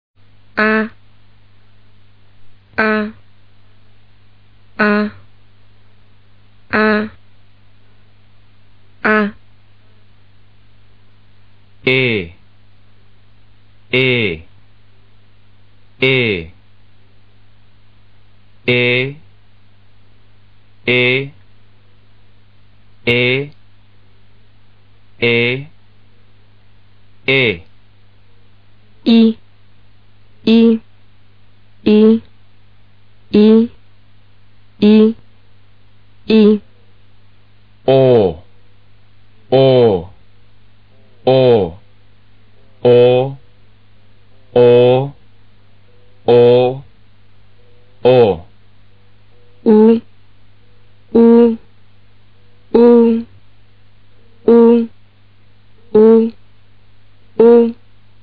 元音发音：
① A 是非圆唇低元音，音标是[a]，发音与汉语的“啊”相似，但是开口程度偏小，注意：可不是像喊“啊”的发音哈！因为它的发音部位在口腔的中部。
③ I 是非圆唇高前元音， 音标是[i]， 发音类似汉语的 “衣”，发音部位在口腔的中前部。
⑤ U 是圆唇高后元音，音标是[u]， 开口小一点， 双唇噘圆， 比O更前突起才好，发音类似“唔”的发音，发音部位是口腔的后部。